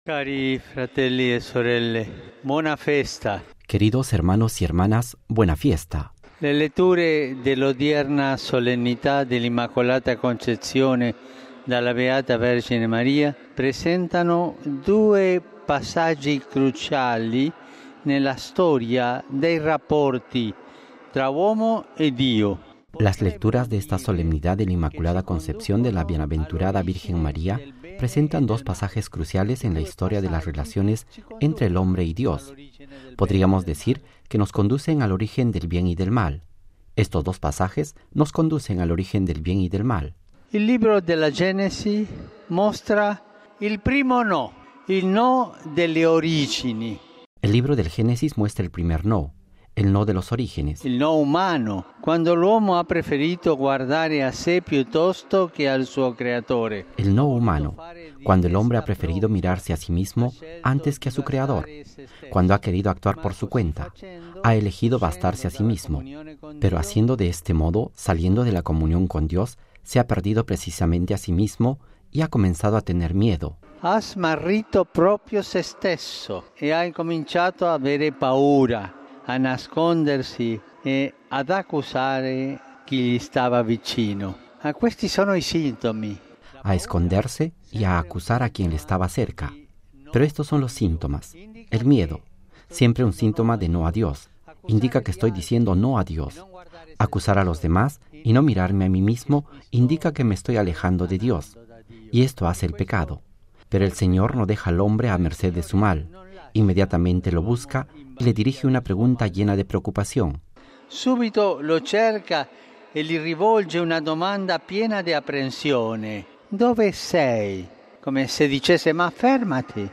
Texto y audio de las palabras del Santo Padre Francisco antes de rezar el Ángelus: